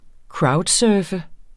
Udtale [ ˈkɹɑwdˌsœːfə ]